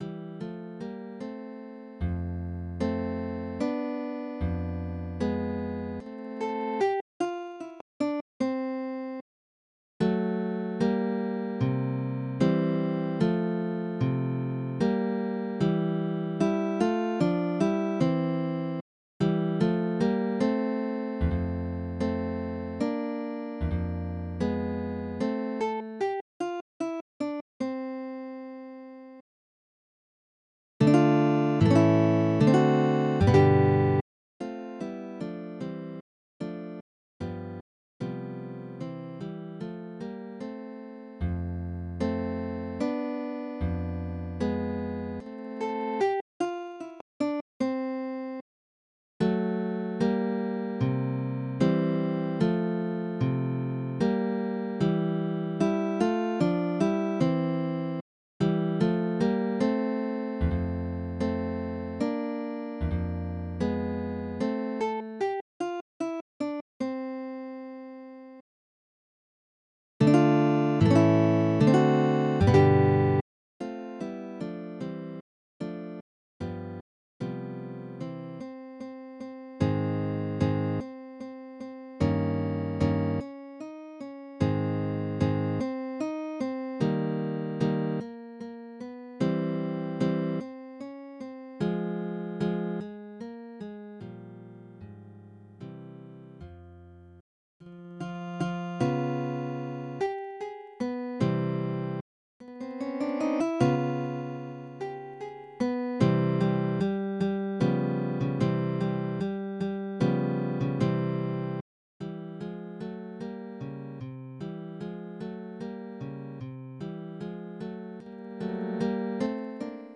Midi音楽が聴けます 2 140円